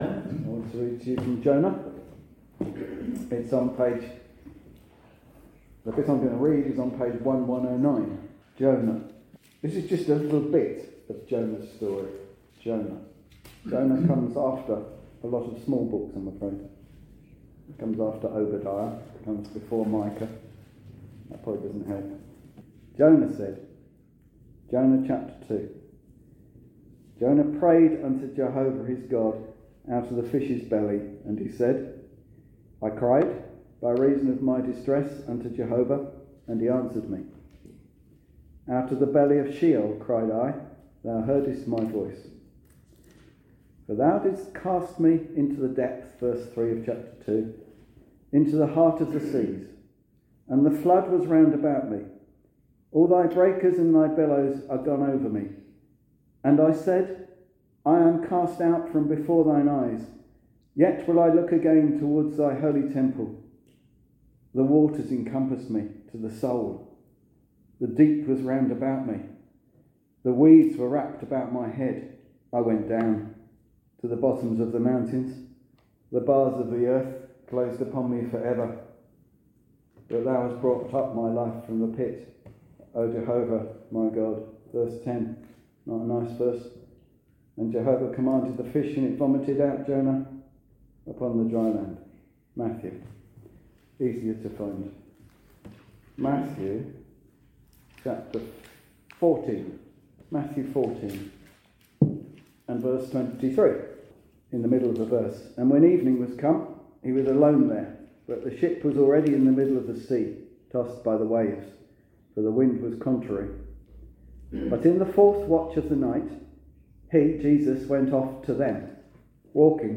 In this Gospel preaching you will hear about the story of Jonah and how God used him to deliver a message of good news for the people of Nineveh.